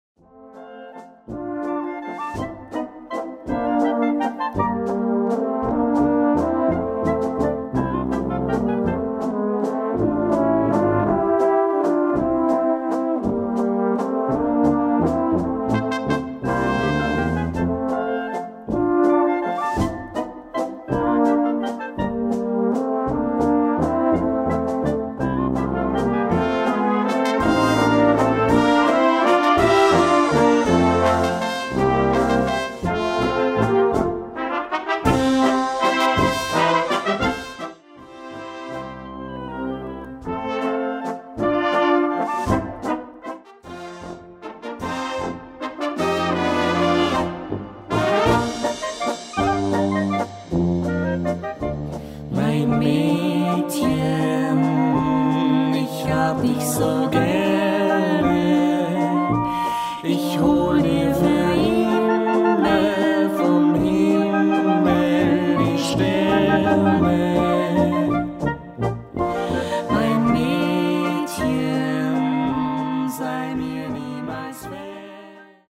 Walzer mit Gesang
Walzer für Blasorchester mit Gesangsduo